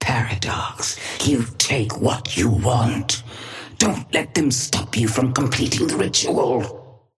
Sapphire Flame voice line - Paradox, you take what you want.
Patron_female_ally_chrono_start_02.mp3